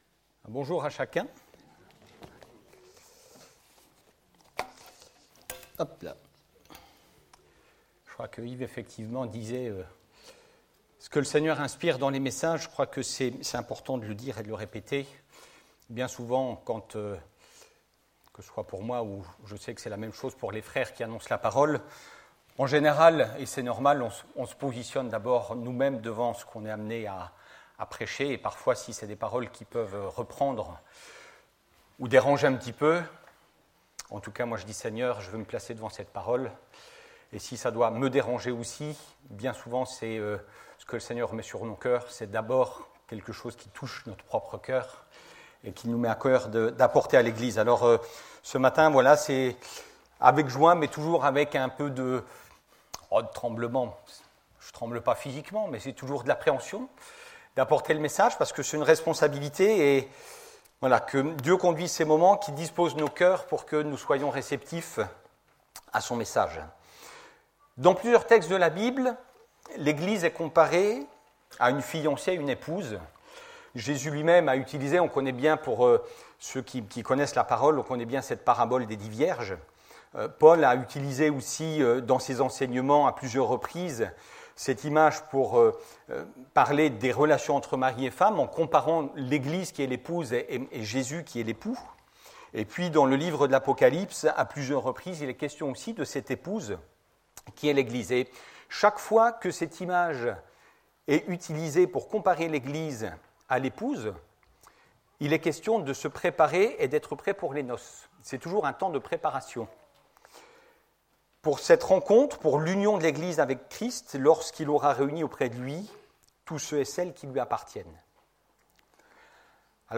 Messages audio | La Bonne Nouvelle